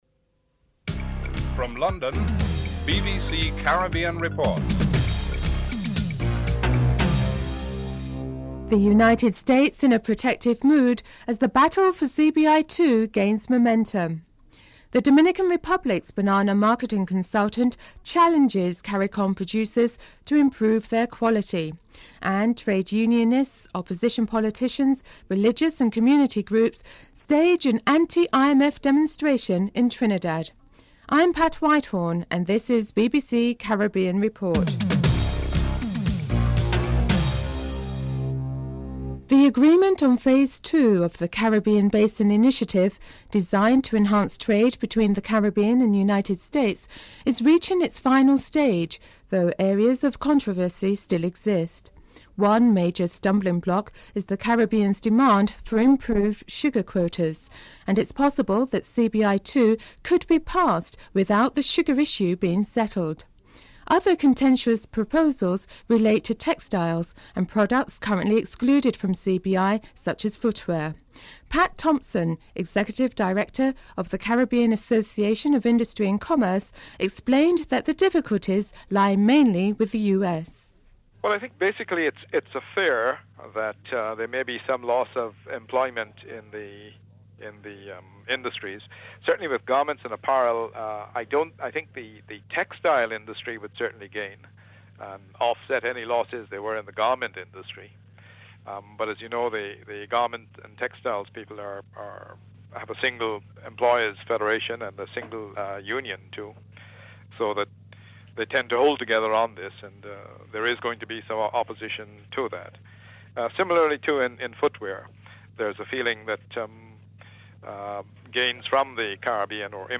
Includes musical interlude at the beginning of the report.
4. Financial News (07:32-09:07)